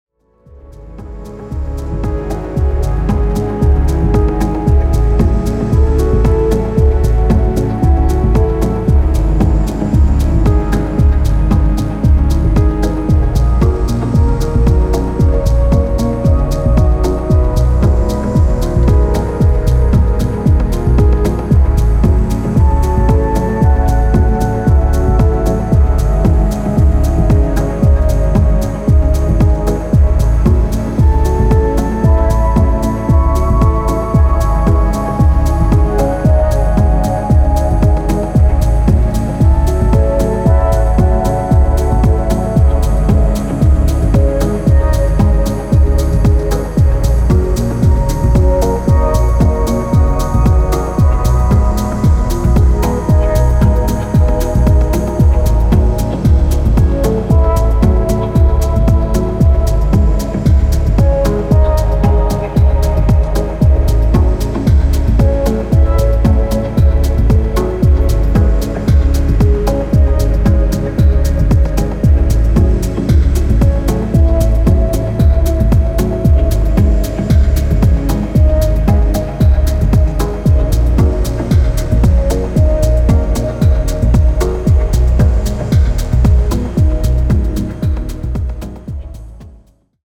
Techno Ambient Dub Techno